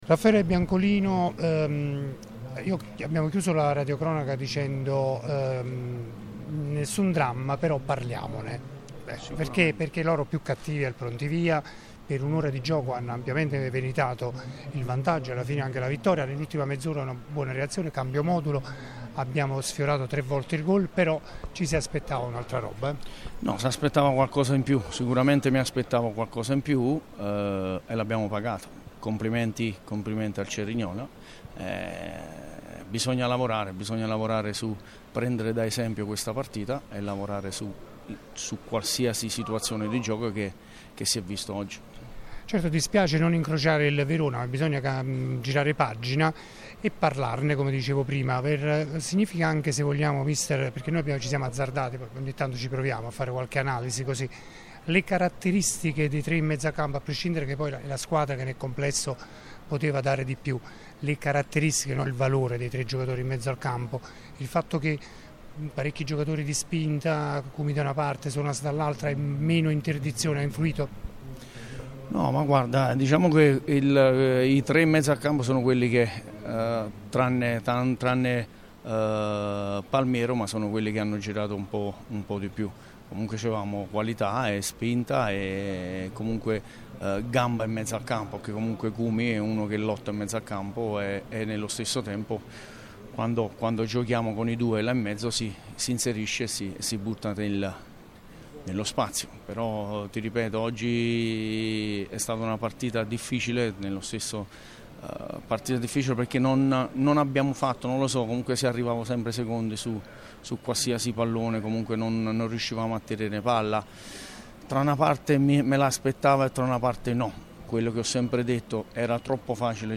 nella consueta conferenza stampa post gara